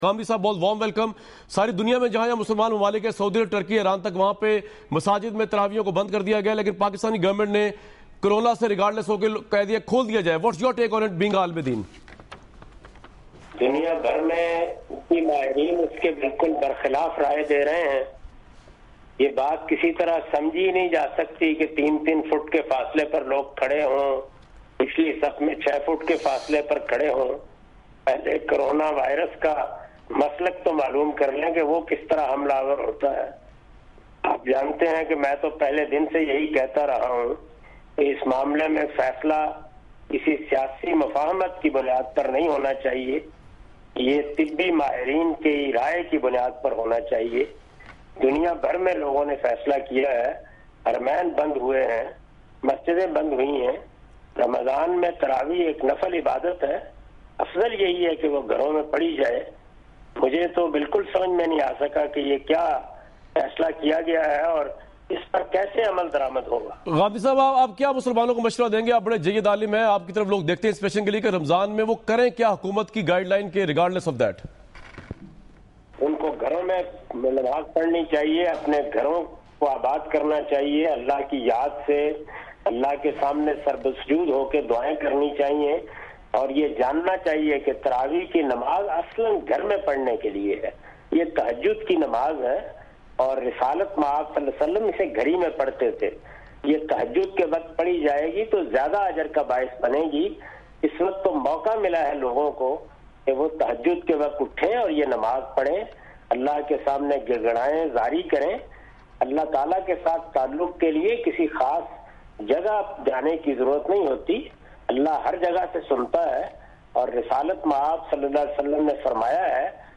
Category: TV Programs / Dunya News / Questions_Answers /
Javed Ahmad Ghamidi answers some important question about corona virus on Dunya News, 22 April 2020.
دنیا نیوز کے اس پروگرام میں جناب جاوید احمد صاحب غامدی کورونا وائرس سے متعلق کچھ اہم سوالات کا جواب دے رہے ہیں۔